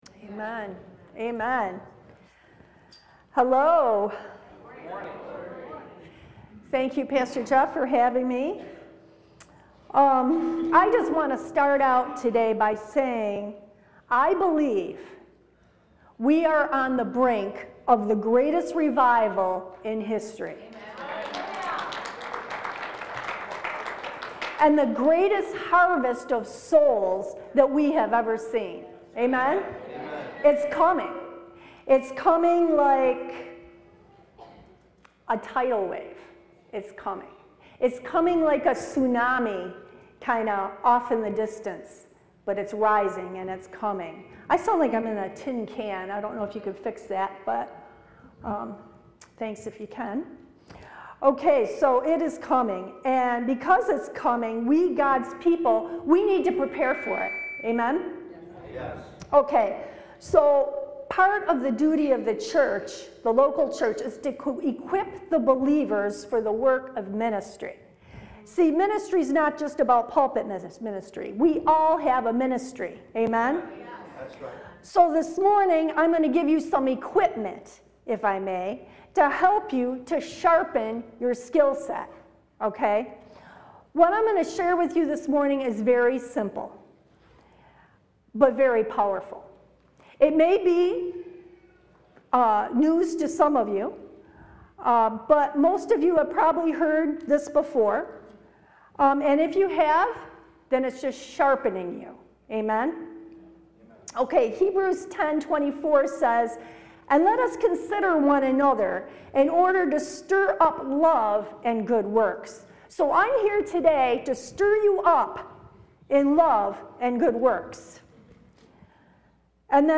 Stand Alone Message